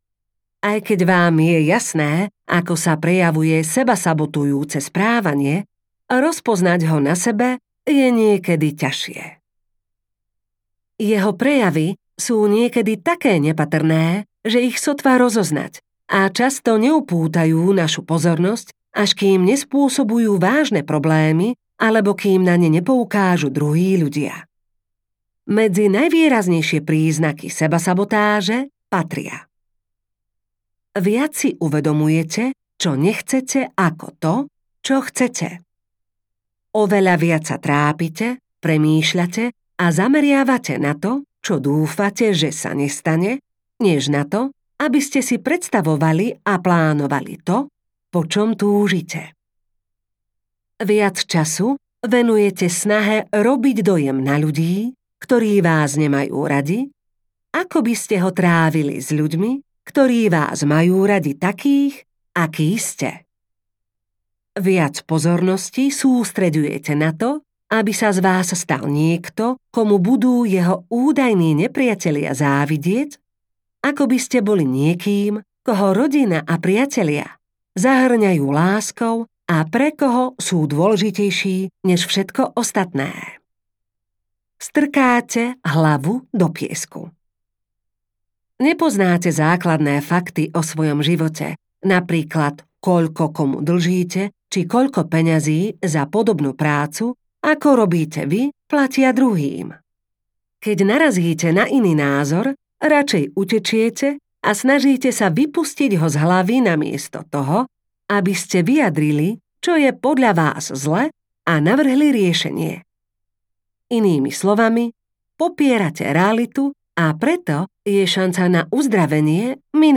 Tým vrchom ste vy audiokniha
Ukázka z knihy